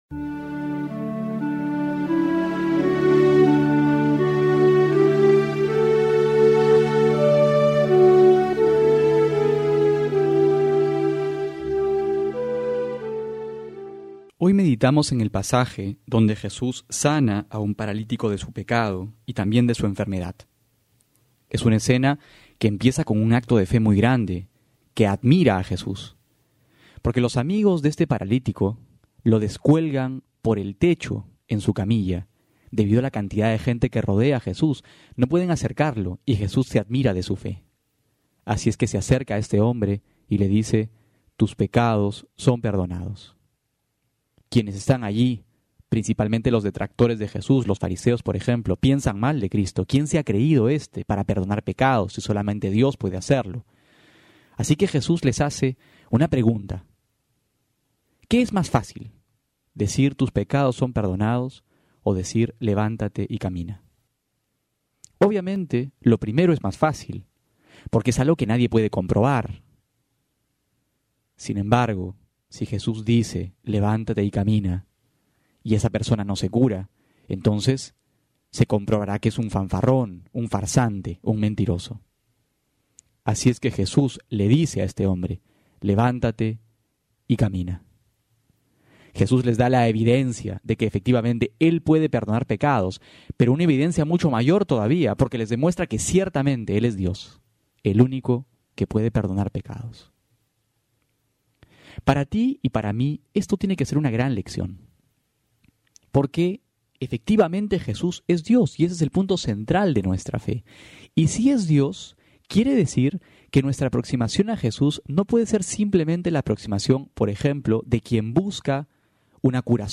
Homilía para hoy: Marcos 2,1-12
febrero19-12homilia.mp3